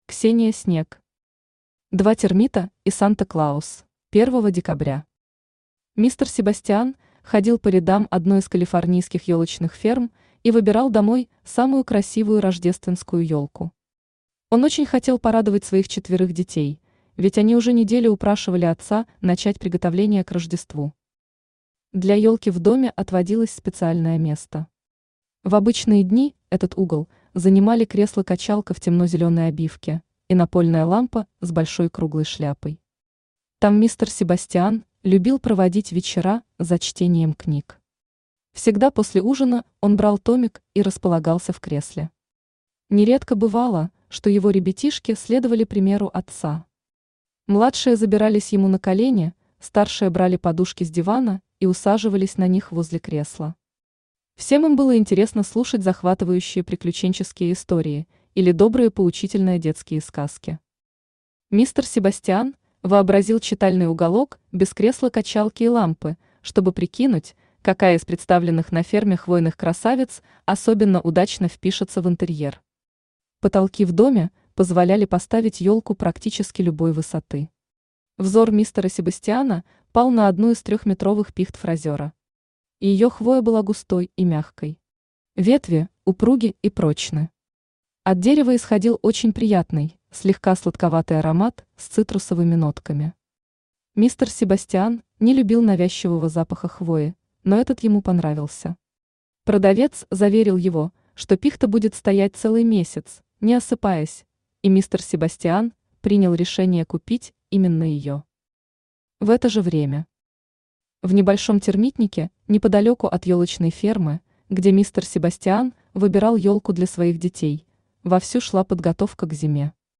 Аудиокнига Два термита и Санта-Клаус | Библиотека аудиокниг
Aудиокнига Два термита и Санта-Клаус Автор Ксения Снег Читает аудиокнигу Авточтец ЛитРес.